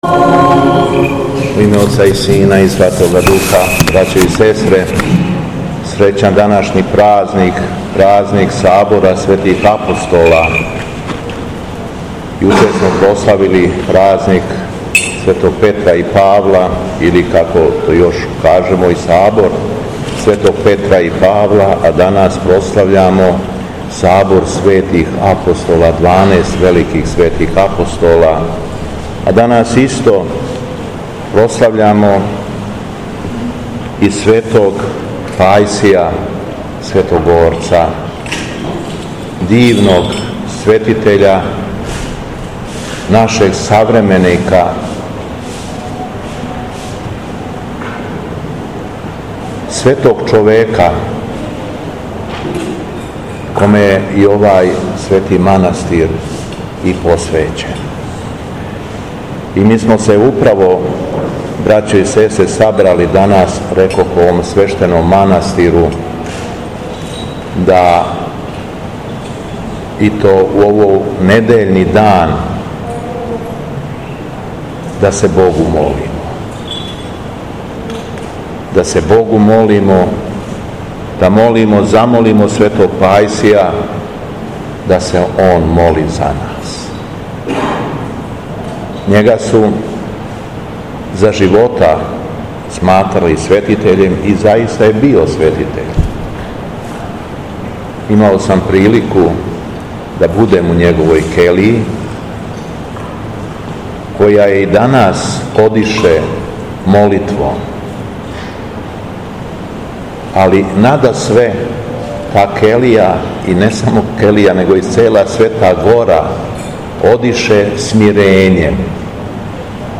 СВЕТА АРХИЈЕРЕЈСКА ЛИТУРГИЈА У МАНАСТИРУ СВЕТОГ ПАЈСИЈА СВЕТОГОРЦА У ШУЉКОВЦУ КРАЈ ЈАГОДИНЕ - Епархија Шумадијска
Беседа Његовог Високопреосвештенства Митрополита шумадијског г. Јована